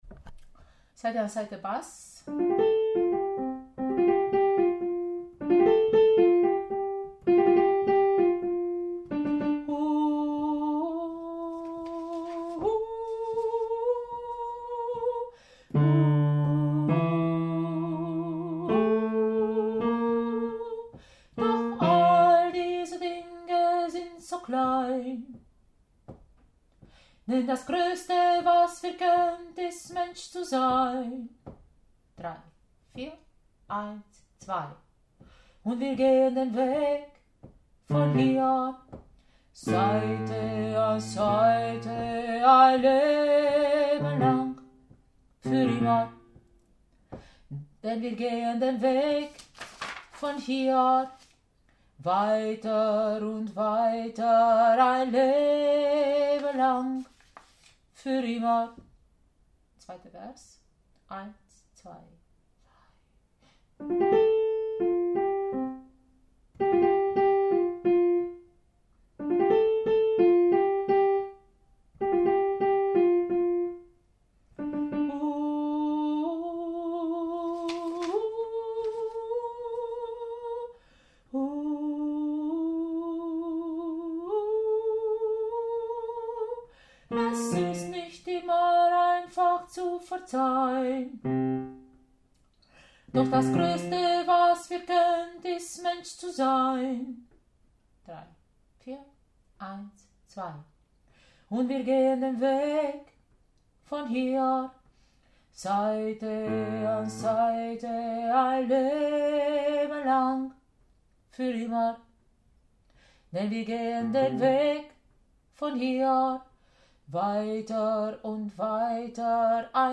Seite an Seite – Bass
Seite-an-Seite-Bass.mp3